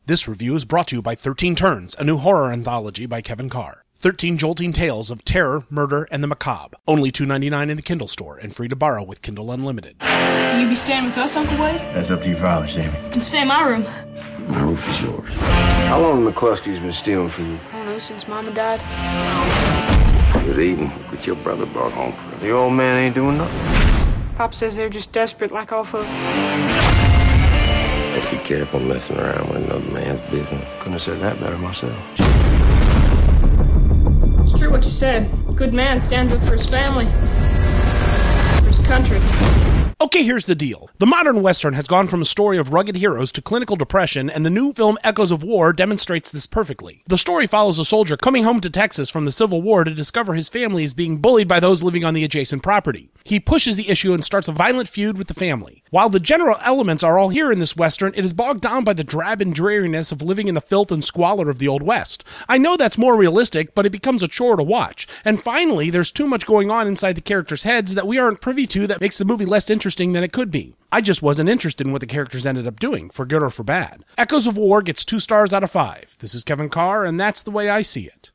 Download this Review